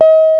JAZZGUITAR 1.wav